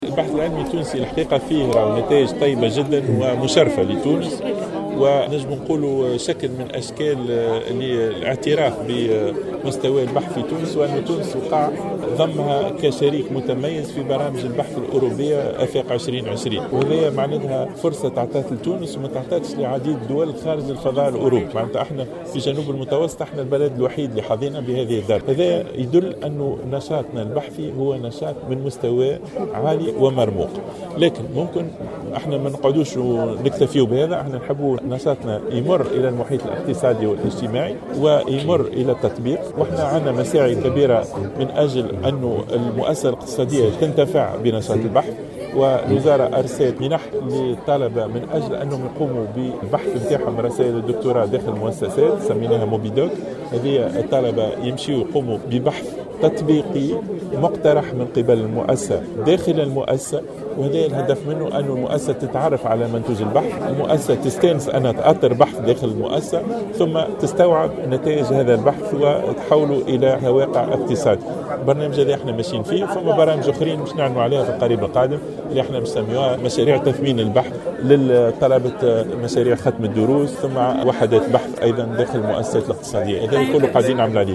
أعلن وزير التعليم العالي شهاب بودن خلال حضوره اليوم الأحد 24 أفريل 2016 تظاهرة طلابية بحي الفجاء بمدنين عن برامج جديدة ومنح ستخصص لفائدة البحث العلمي في تونس.